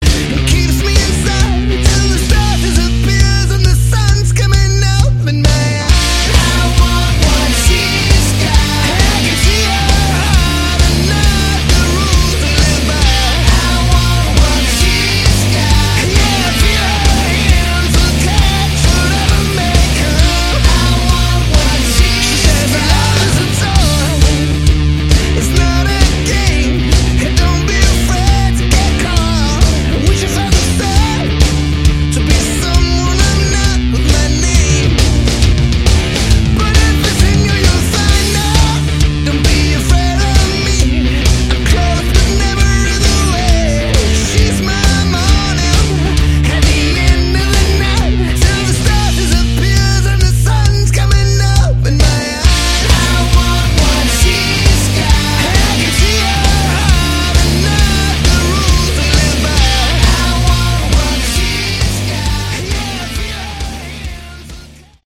Category: Hard Rock
Vocals, Guitar
Drums
Bass